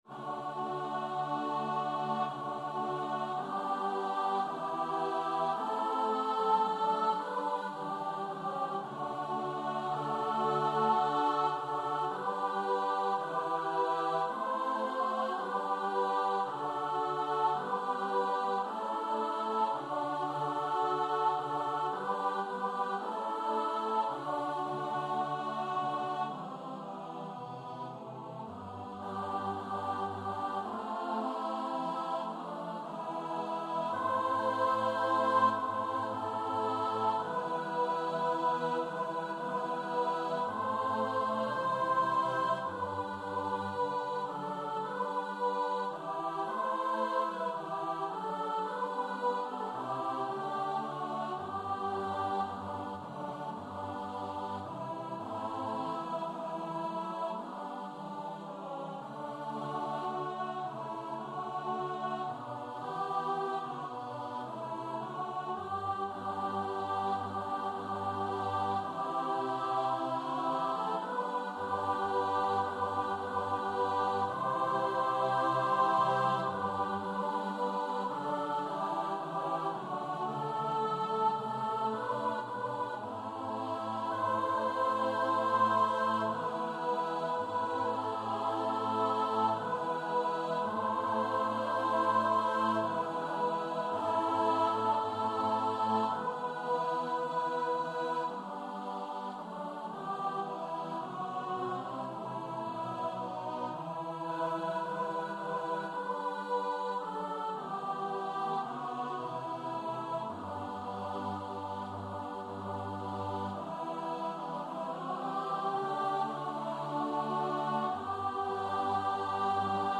Free Sheet music for Choir
CantusAltusTenor I & 2Bassus I & II
4/2 (View more 4/2 Music)
G major (Sounding Pitch) (View more G major Music for Choir )
Classical (View more Classical Choir Music)